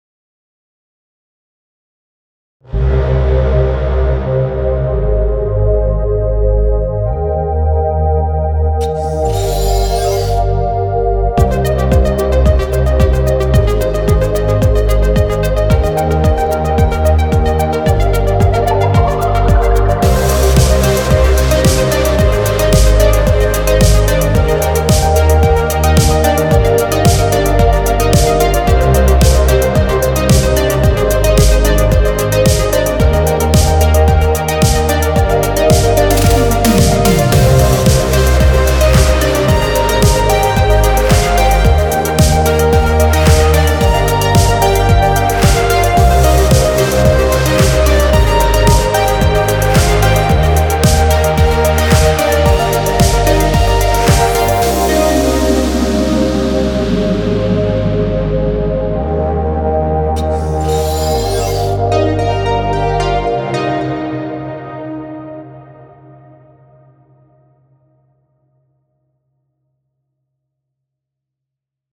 SOUND IDENTITY
It had to be fresh and electric.